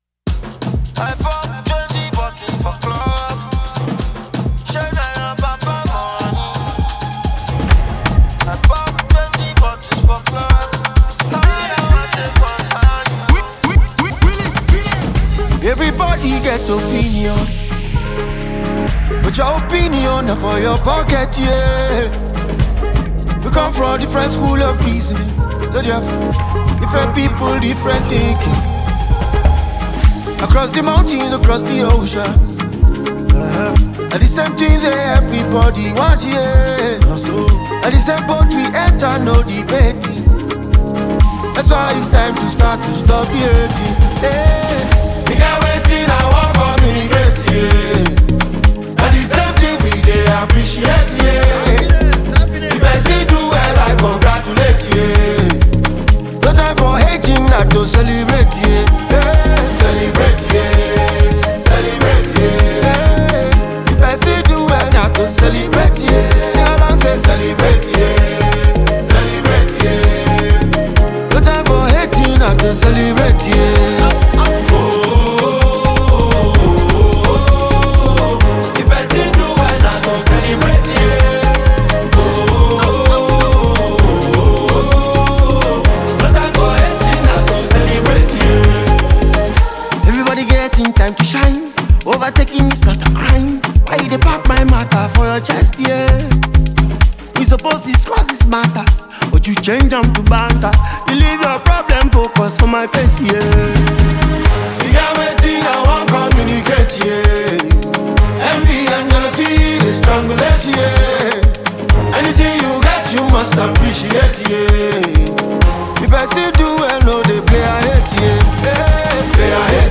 feel-good anthem